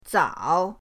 zao3.mp3